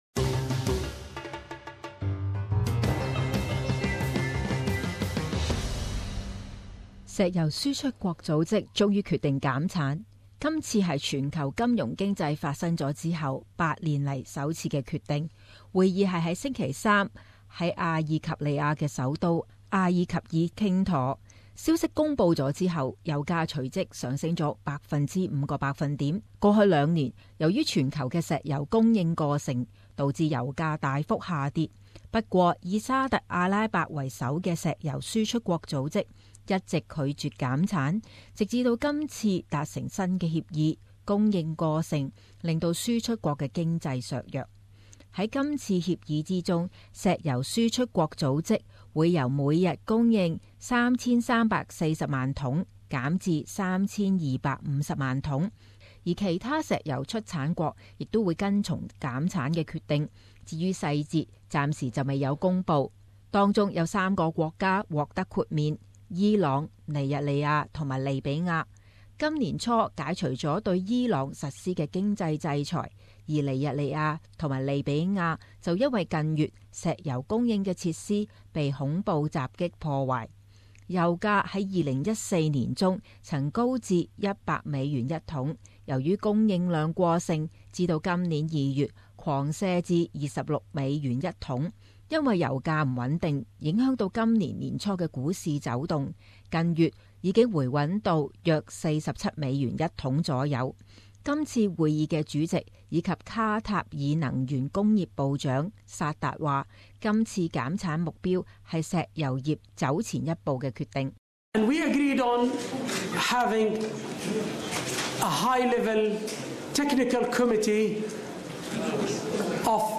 【時事報導】石油輸出國組織同意減產石油